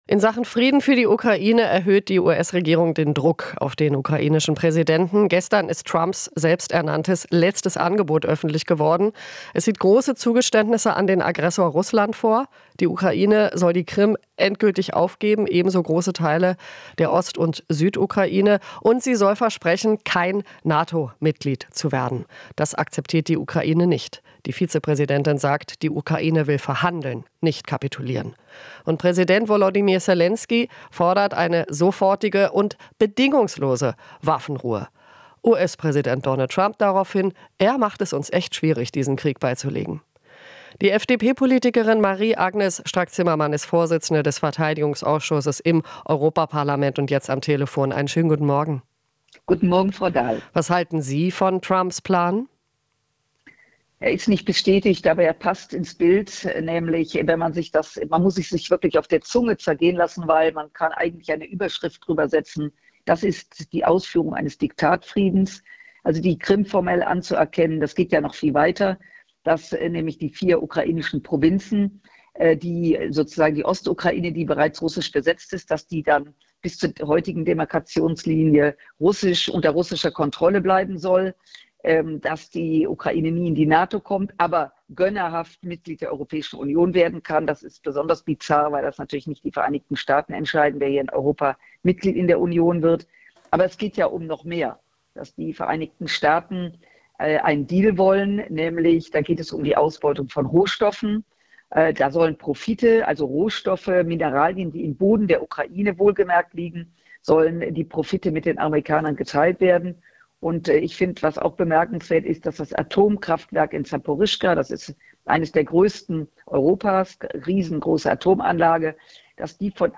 Interview - Strack-Zimmermann (FDP): "Trump-Plan wäre Diktatfrieden"